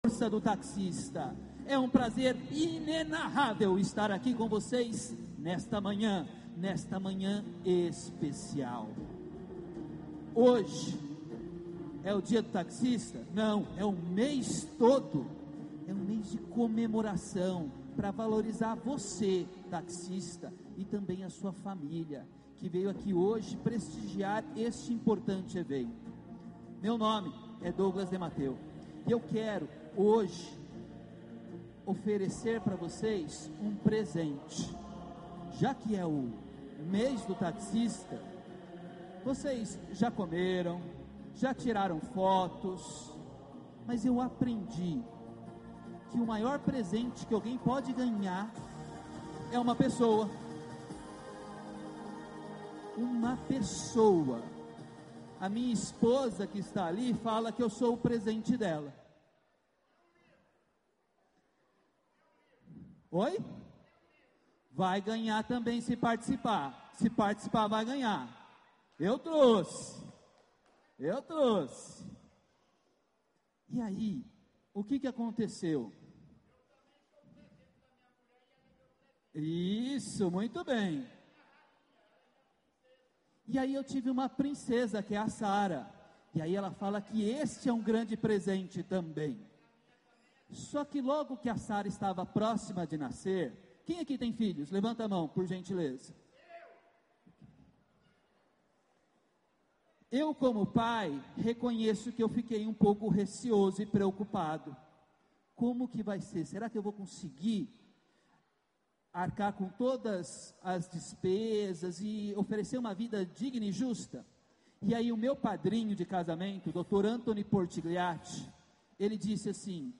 IAPerforma no Encontro de Taxistas de São Paulo
Áudio da Palestra